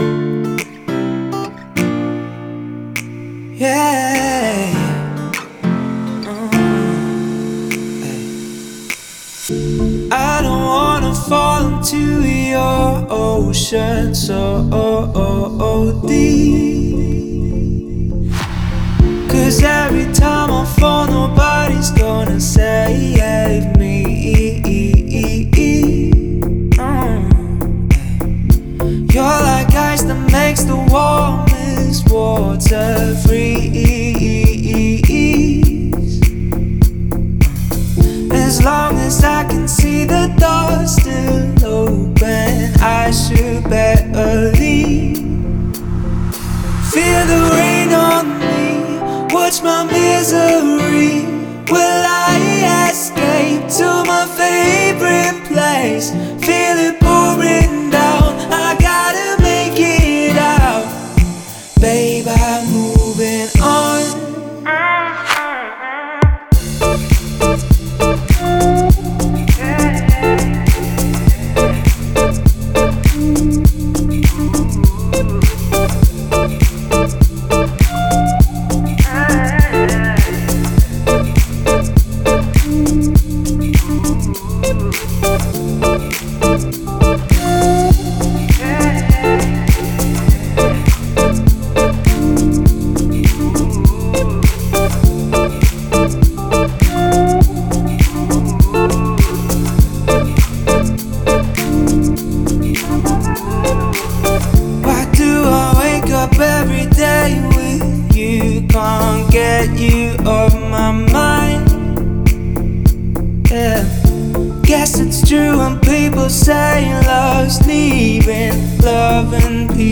энергичная электронная танцевальная композиция